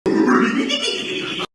Download Free Scooby Sound Effects